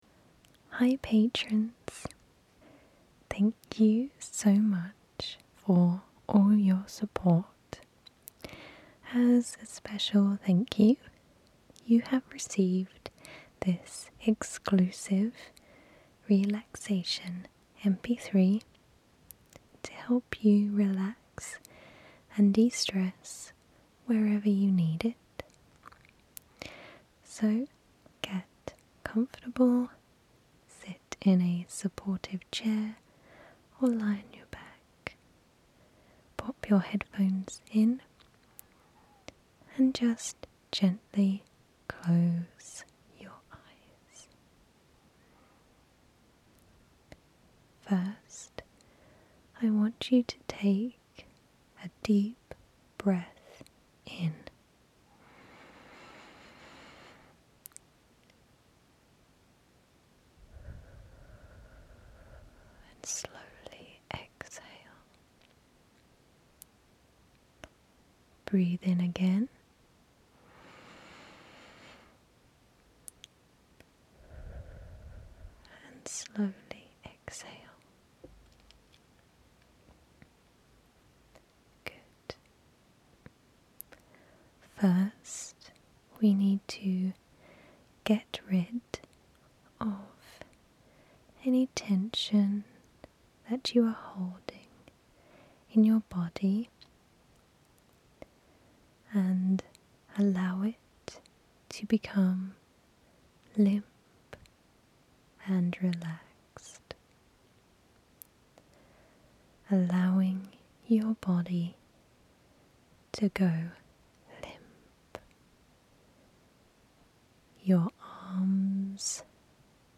Guided Relaxation!